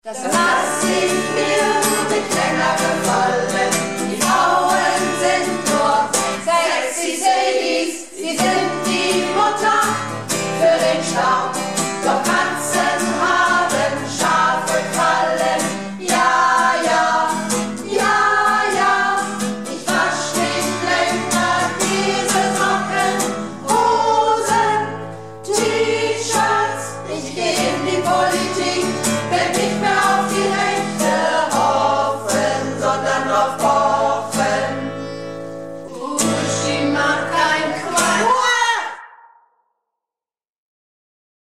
Projektchor "Keine Wahl ist keine Wahl" - Probe am 21.05.19